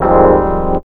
CHORD06 01-L.wav